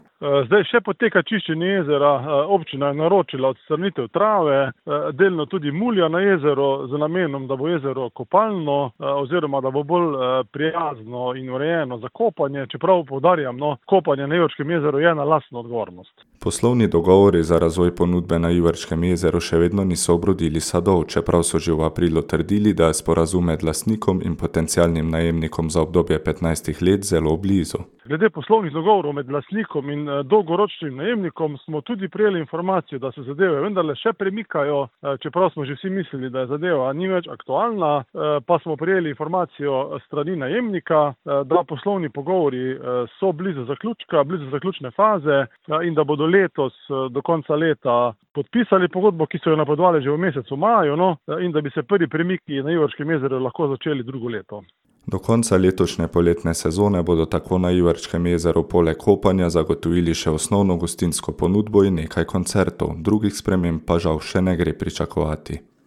Ravenski župan Tomaž Rožen: